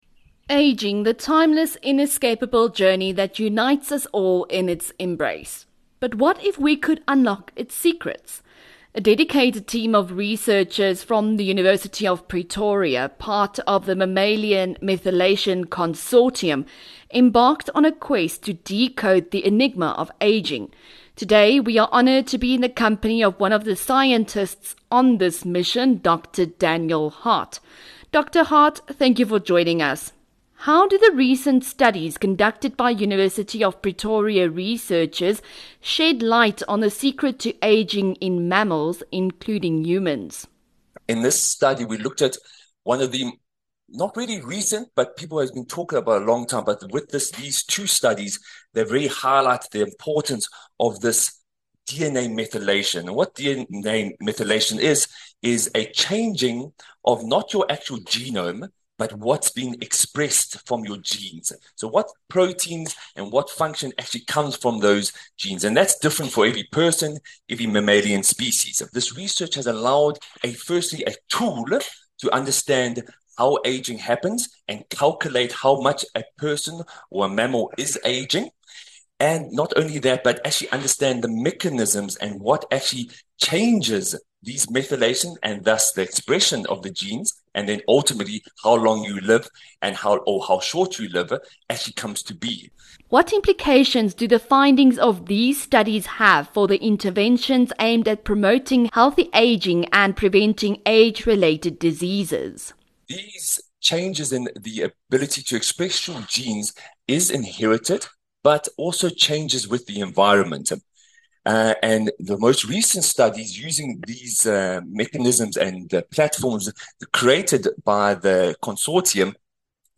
6 Nov INTERVIEW